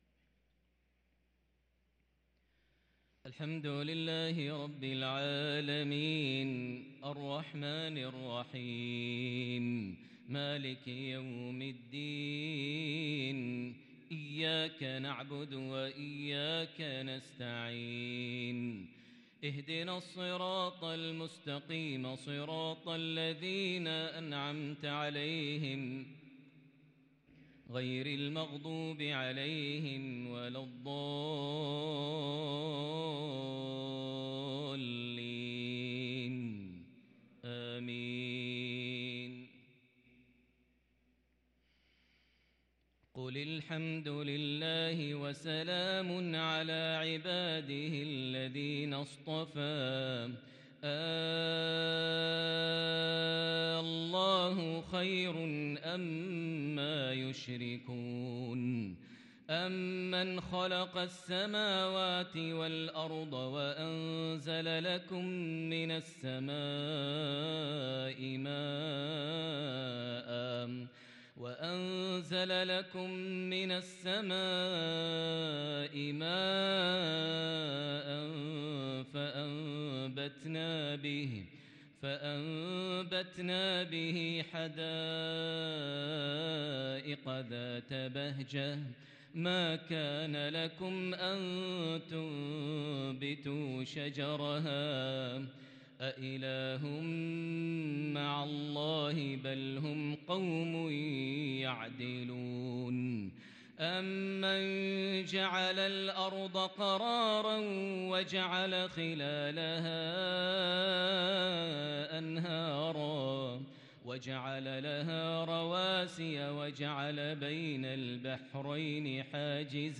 صلاة العشاء للقارئ ماهر المعيقلي 18 ربيع الآخر 1444 هـ
تِلَاوَات الْحَرَمَيْن .